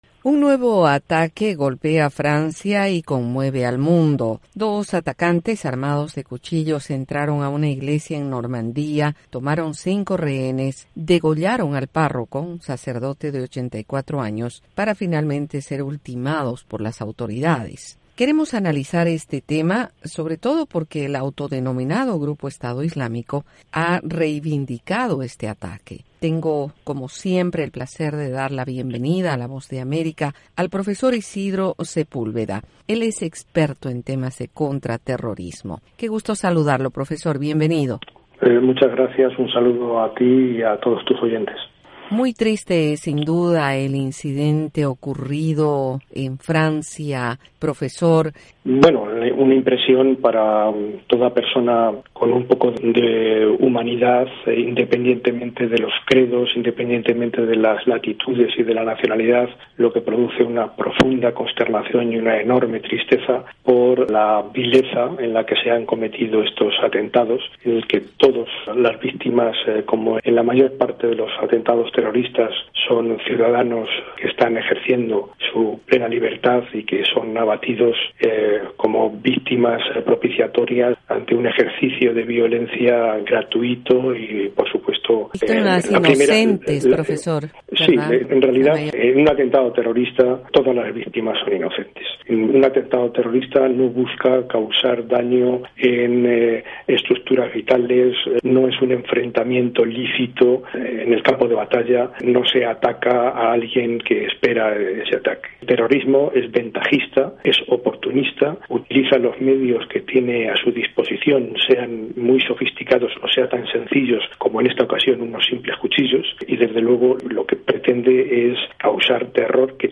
analiza el tema en entrevista con la Voz de América desde Madrid